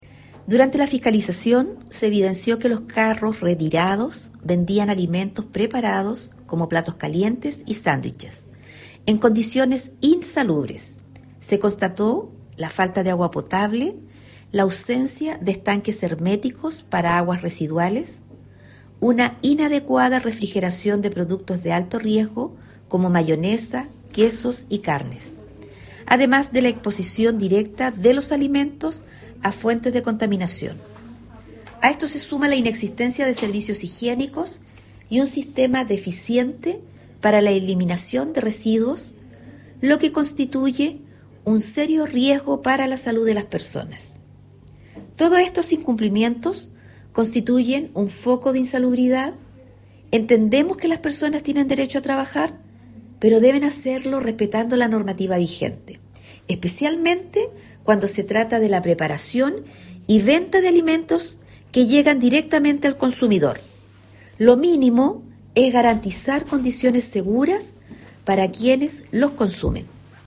La Seremi de Salud, Jéssica Rojas Gahona, explicó que durante la fiscalización se constató una serie de incumplimientos graves.